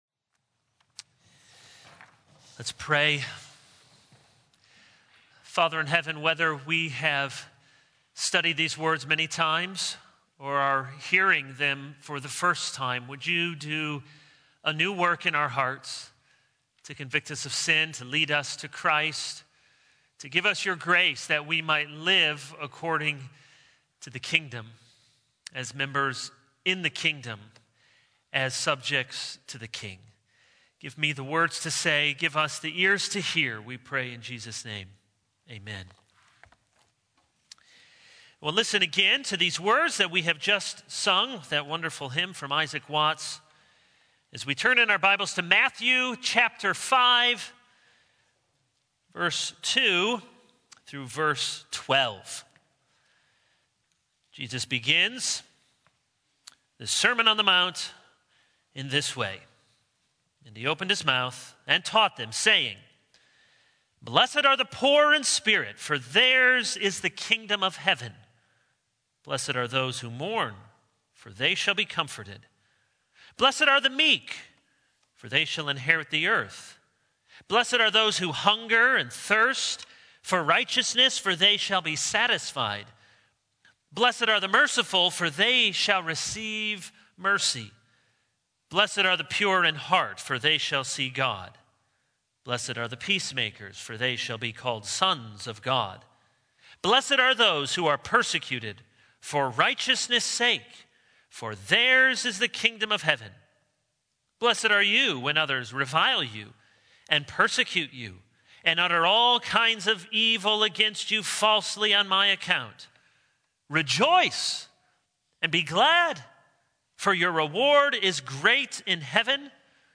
January 30, 2022 | Sunday Evening